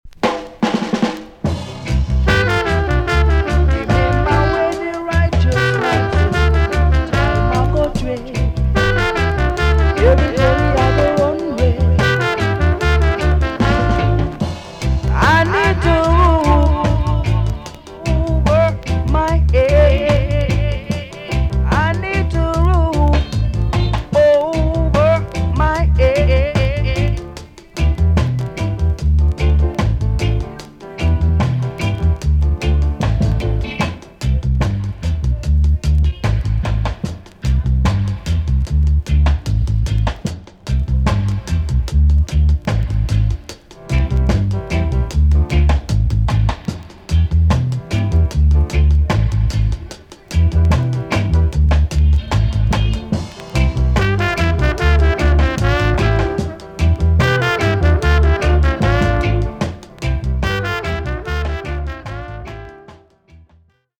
TOP >SKA & ROCKSTEADY
B.SIDE Version
EX- 音はキレイです。